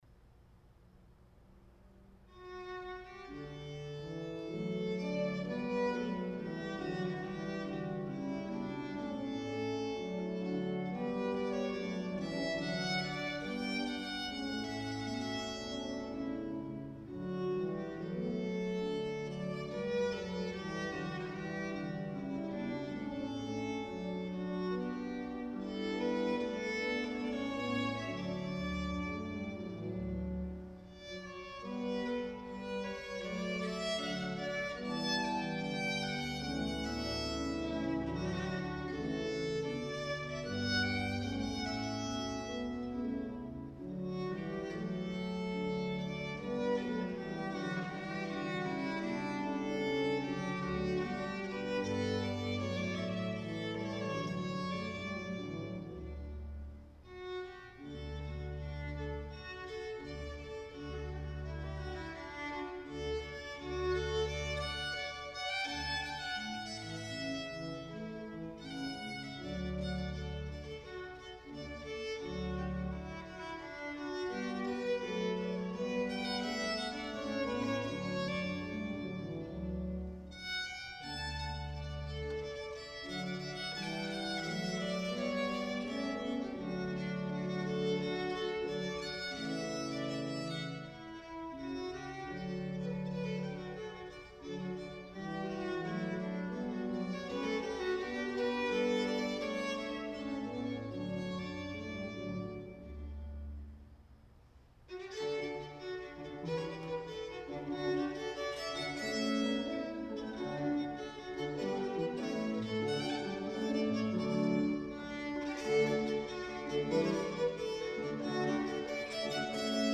Music for Violin and Organ